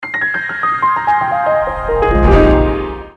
• Качество: 320, Stereo
без слов
пианино
Сообщение с перебором по клавишам